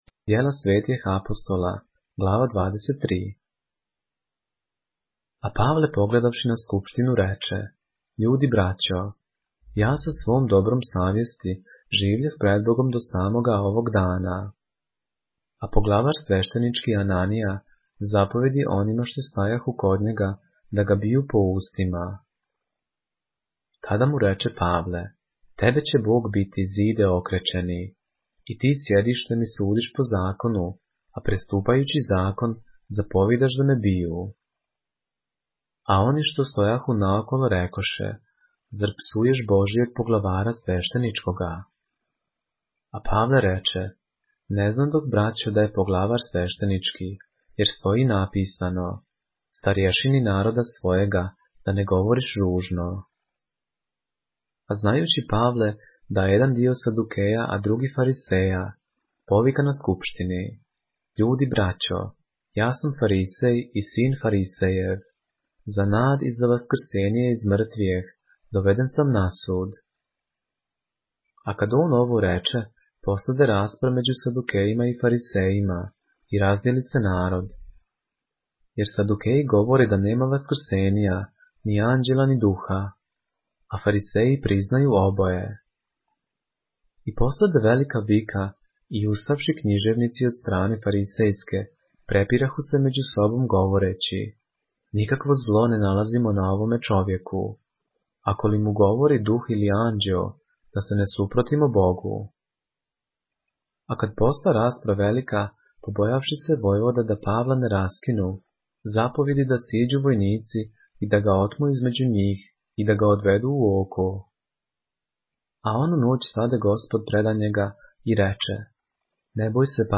поглавље српске Библије - са аудио нарације - Acts, chapter 23 of the Holy Bible in the Serbian language